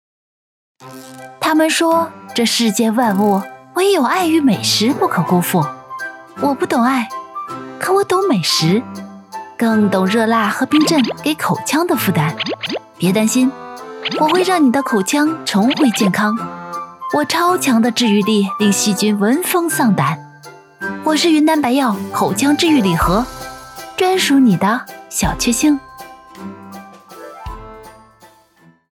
女配音-配音样音免费在线试听-第72页-深度配音网
女389年轻配音 v389